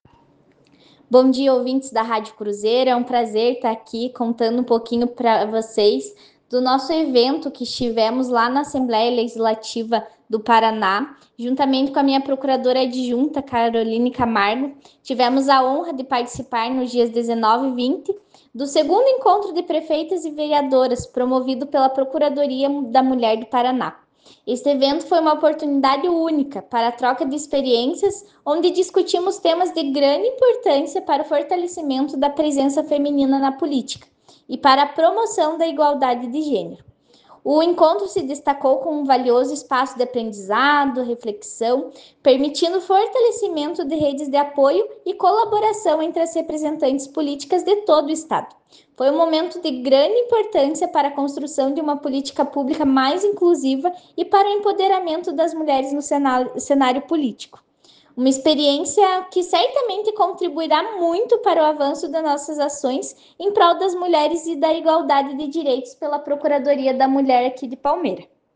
Fabíola Mereles falou com a CRUZEIRO sobre o evento, ouça: